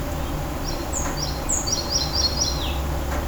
малая мухоловка, Ficedula parva
СтатусСлышен голос, крики